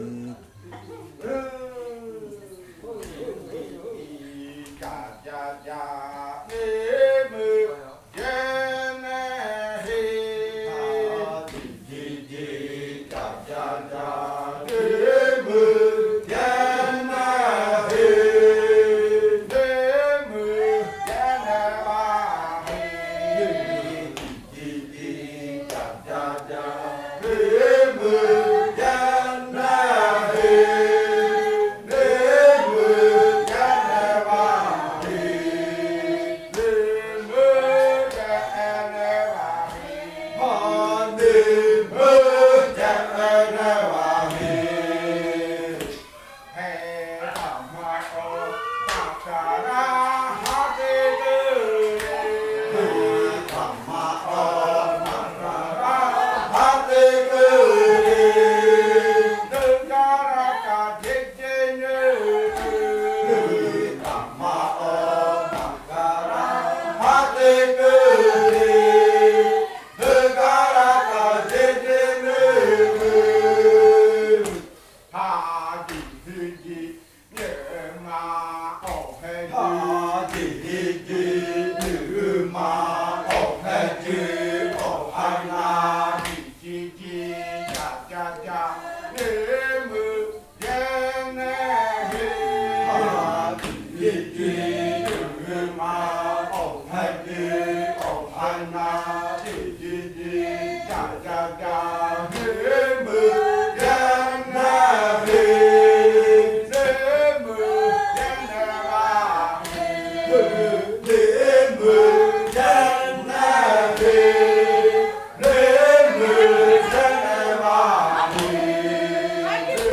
Canto de la variante jimokɨ